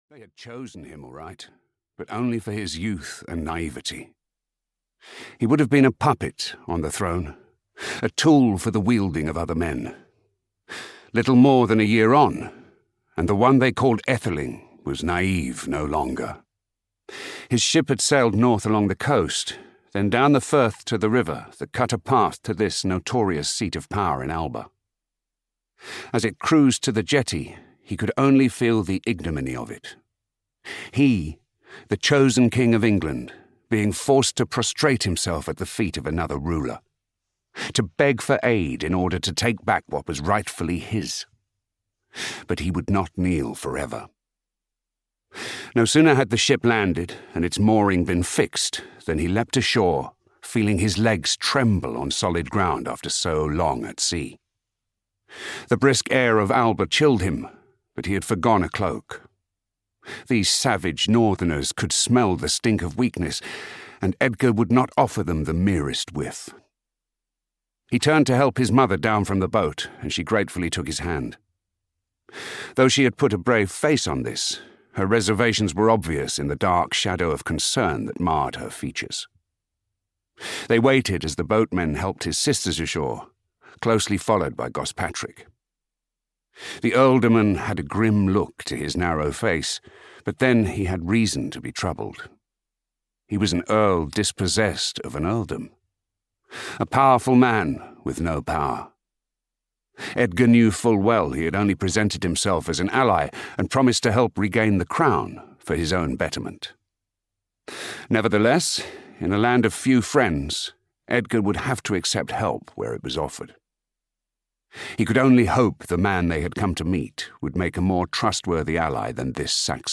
Shield Breaker (EN) audiokniha
Ukázka z knihy